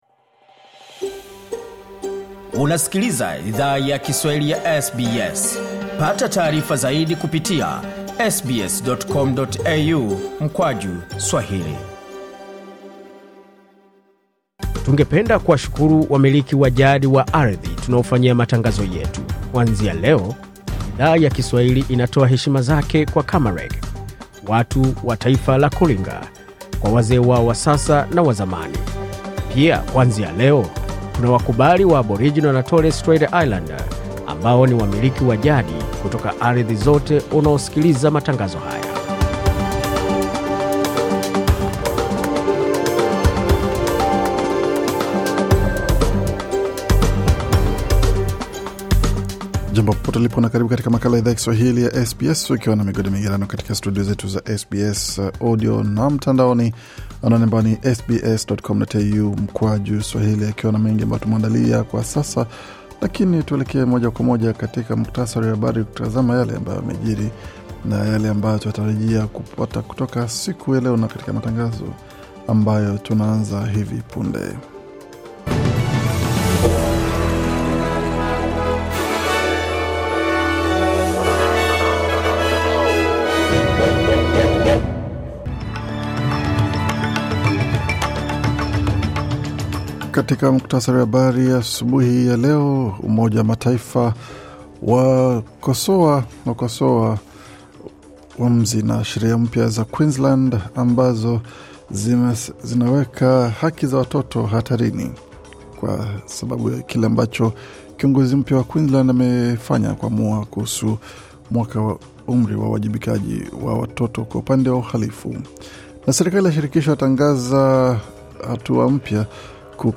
Taarifa ya Habari 3 Disemba 2024